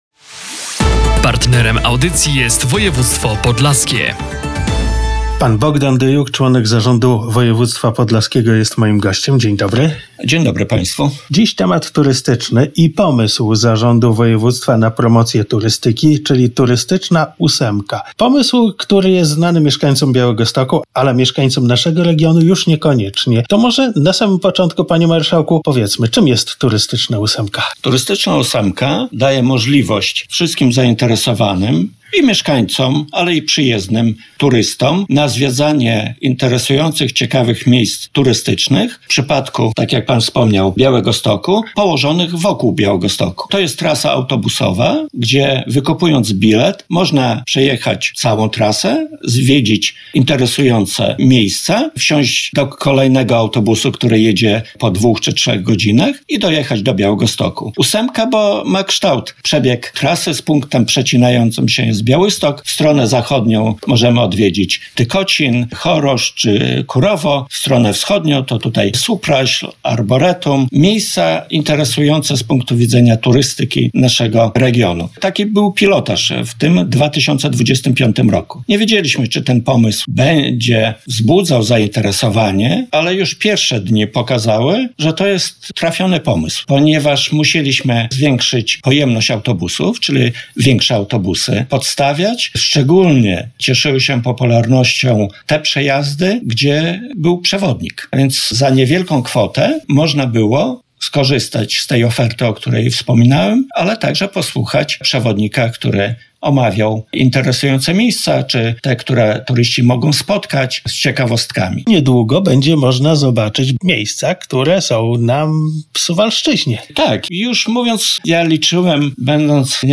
Szczegóły w rozmowie z Bogdanek Dyjukiem, Członkiem Zarządu Województwa Podlaskiego.